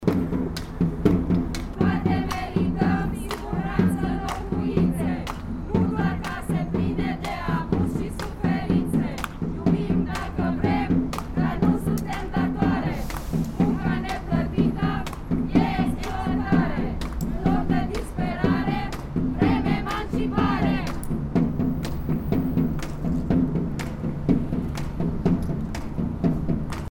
Ulterior, revendicările au prins forma unui performance: „Iubim dacă vrem, nu suntem datoare, Munca neplătită, înseamnă exploatare”, au fost două dintre versuri:
Ambiuanta-scandari.mp3